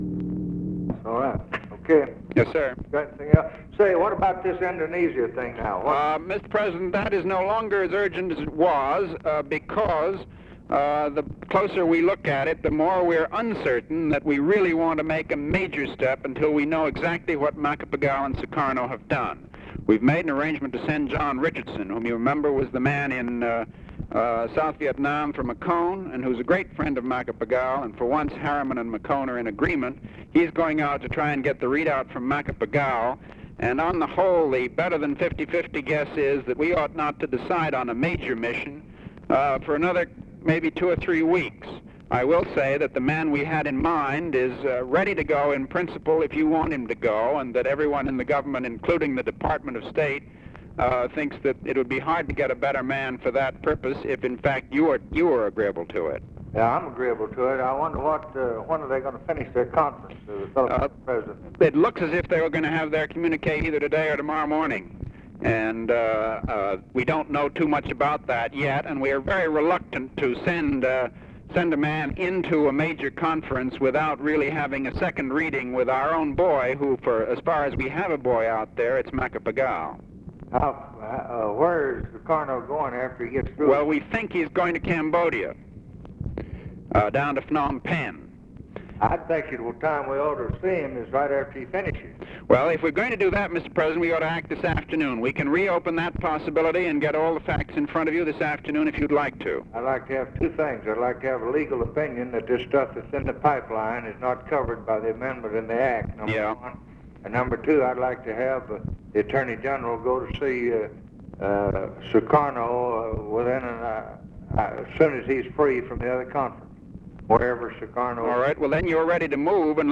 Conversation with MCGEORGE BUNDY, January 10, 1964
Secret White House Tapes